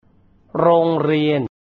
Roong Riian.